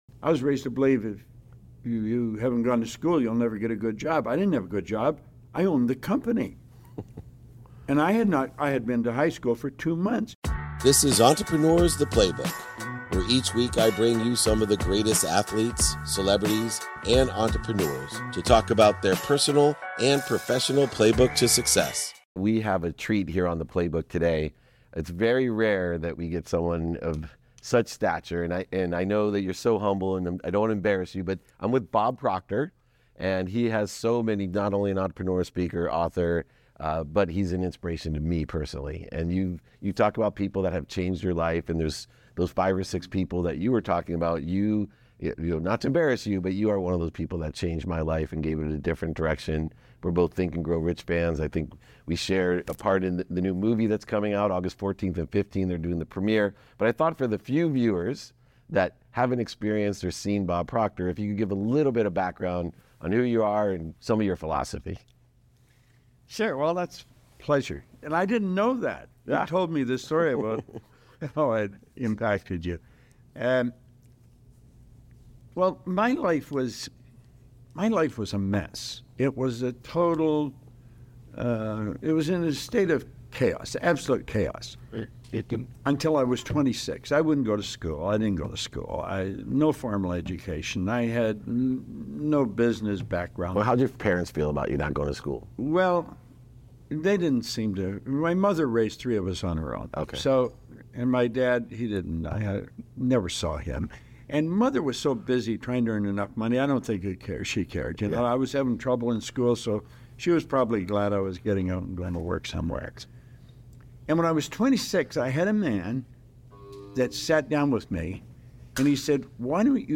A throwback to July 2017 when I sat down with my late mentor and hero Bob Proctor. We dive into Bob’s early ambitions, the importance of doing things for ourselves, and the transformative journey of Bob borrowing $1000 of startup money to achieve remarkable success. Our discussion also explores the essence of understanding and reshaping our subconscious beliefs to unlock a world of possibilities.